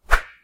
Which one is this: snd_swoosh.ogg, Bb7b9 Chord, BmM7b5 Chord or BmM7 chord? snd_swoosh.ogg